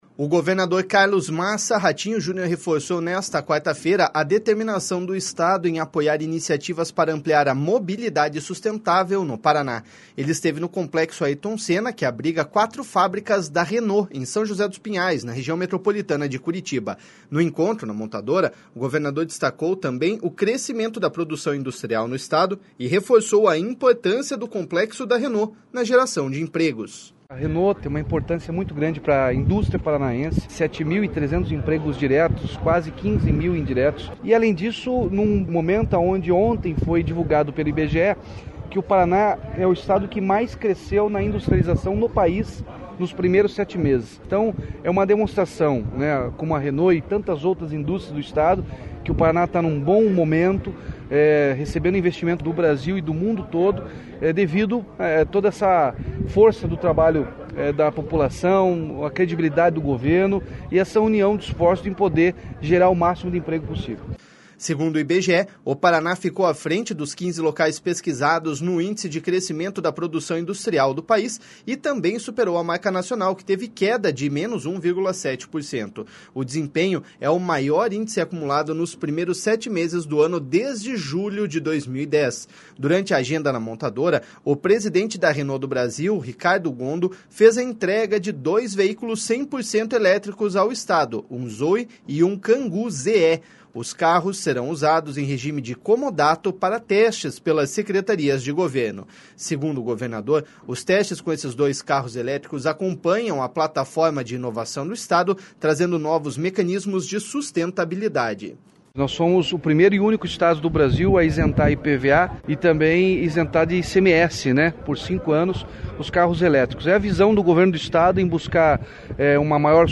No encontro na montadora, o governador destacou também o crescimento da produção industrial no Estado, e reforçou a importância do complexo da Renault na geração de empregos.// SONORA RATINHO JUNIOR.//